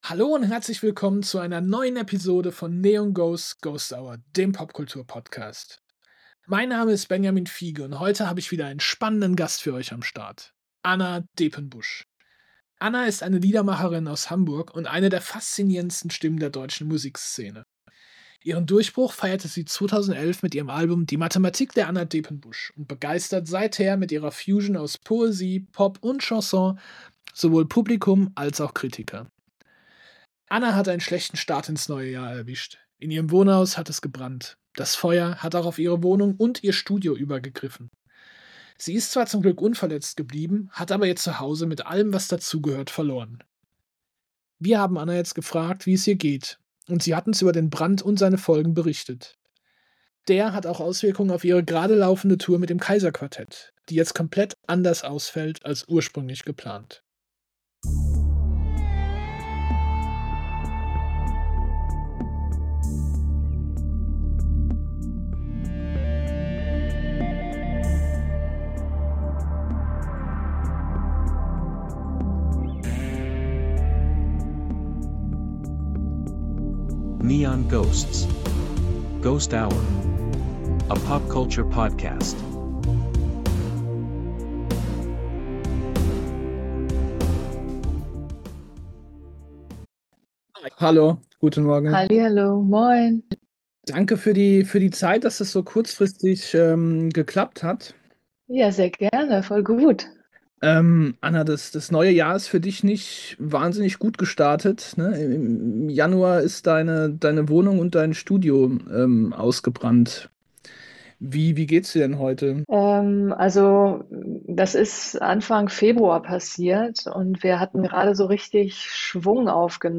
Im Interview: Anna Depenbusch - Der Brand und seine Folgen ~ NEON GHOSTS: GHOST HOUR Podcast